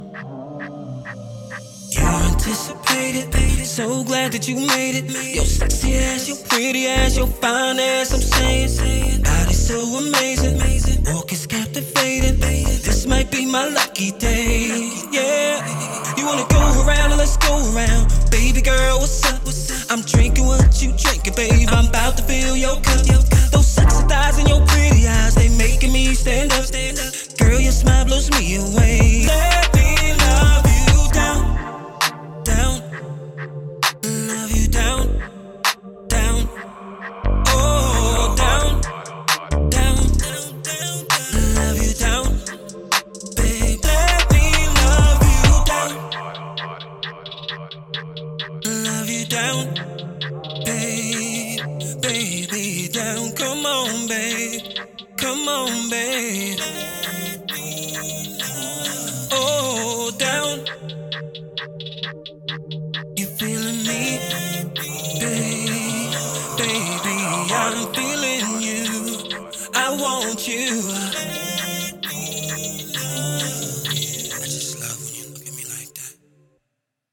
Street Soul meets Pop Passion.